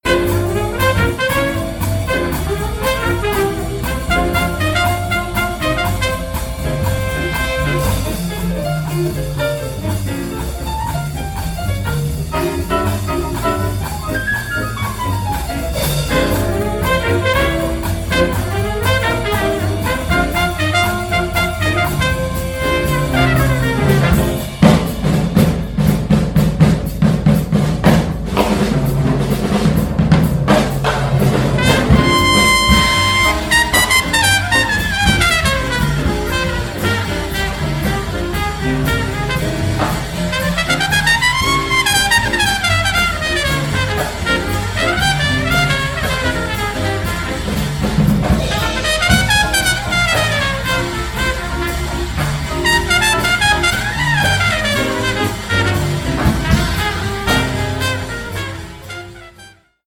trompette
saxophone alto, clarinette
piano
contrebasse
batterie